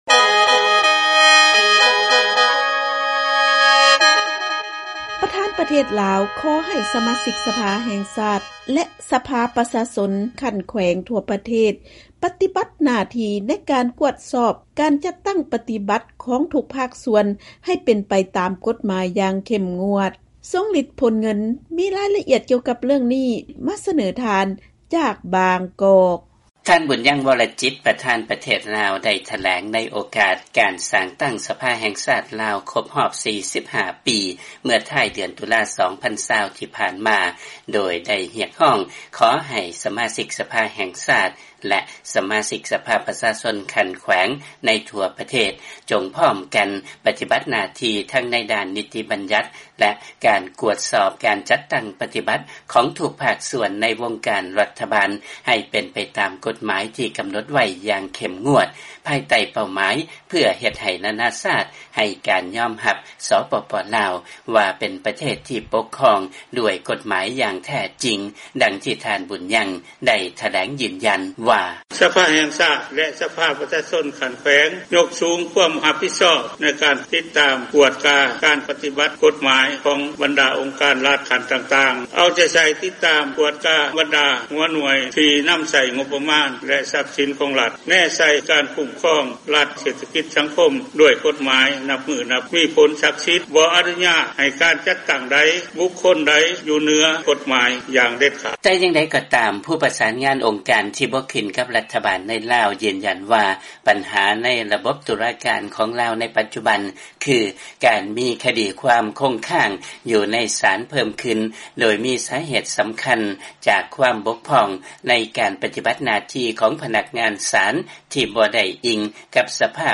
ເຊີນຟັງລາຍງານ ປະທານປະເທດ ຂໍໃຫ້ສະມາຊິກສະພາແຫ່ງຊາດ ແລະຂັ້ນແຂວງທົ່ວປະເທດ ປະຕິບັດໜ້າທີ່ ຕາມກົດໝາຍຢ່າງເຂັ້ມງວດ